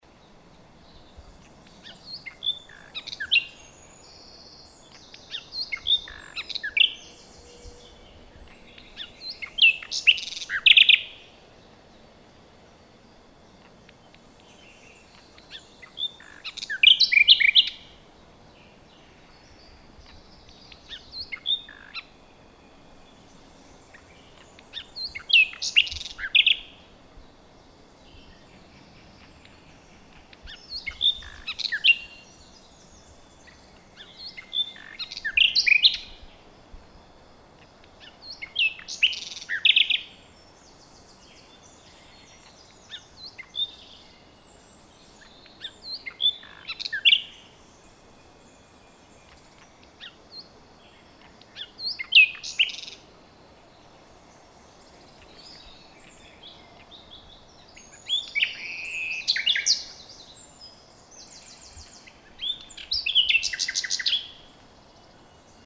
a:1:{s:11:"description";s:18:"Cant del rossinyol";}
Aquest és el cas del rossinyol (Luscinia megarhynchos)que tant canta de dia com de nit. És ara a principis d'abril és fàcil que sentiu el seu potent i melòdic cant, que l'han fet mític tant al folklore català com xinés.